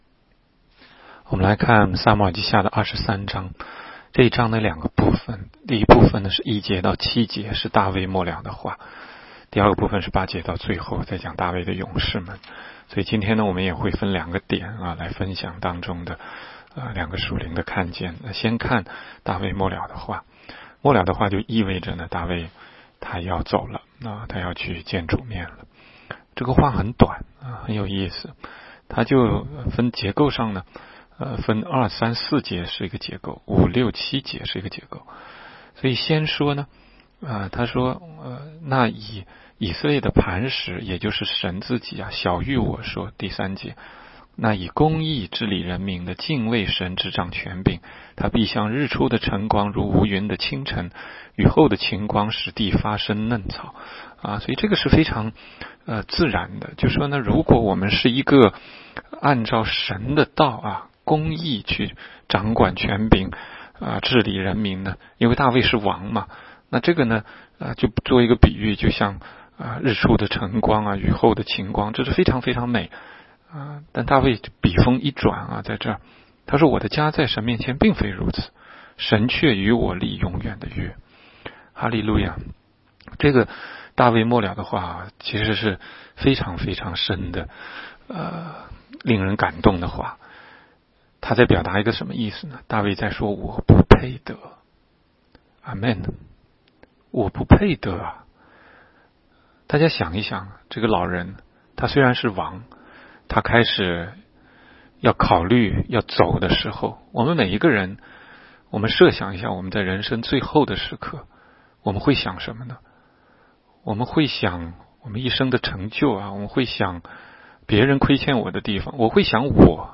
16街讲道录音 - 每日读经-《撒母耳记下》23章